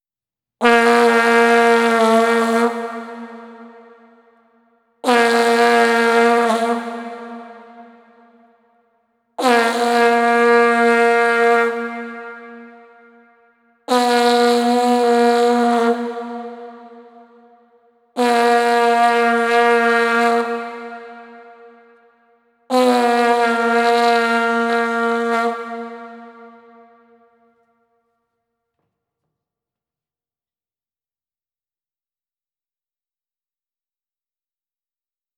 Здесь вы можете слушать и скачивать разные варианты гула: от монотонного гудения до интенсивного рева.
Звук смонтированной вувузелы